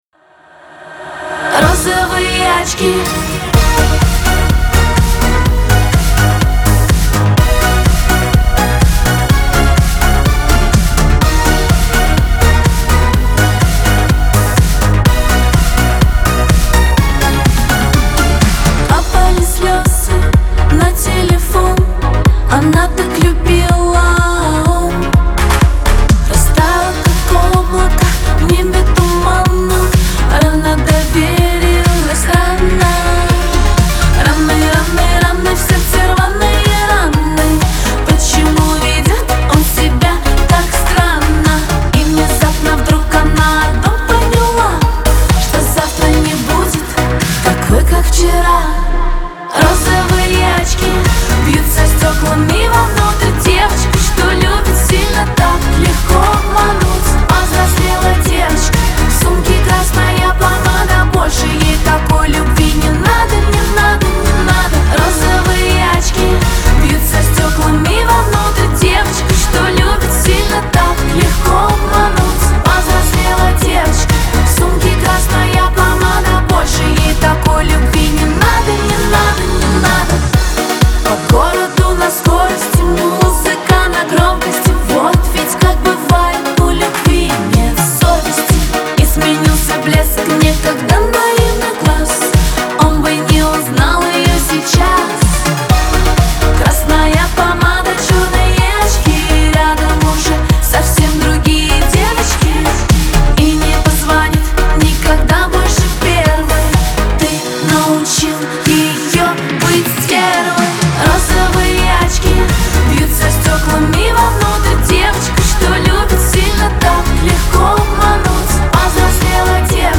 яркая поп-песня